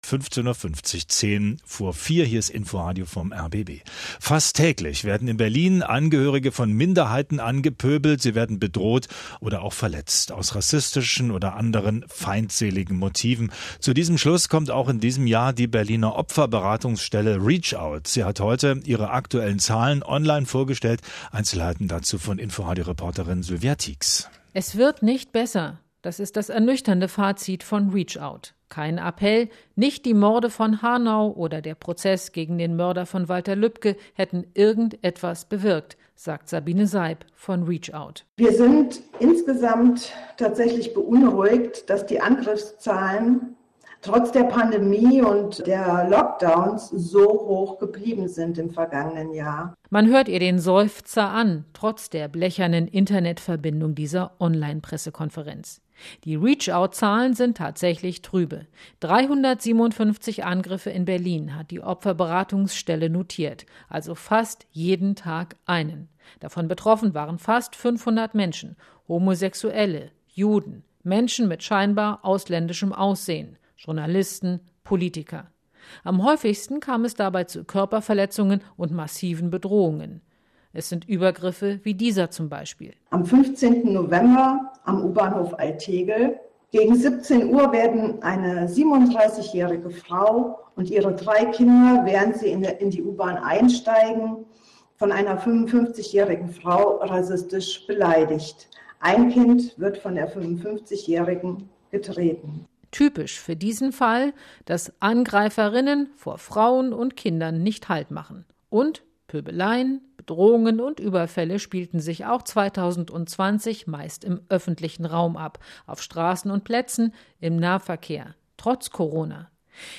rbb Inforadio: Bericht über die Pressekonferenz am 09.03.2021 zu den Angriffen in Berlin 2020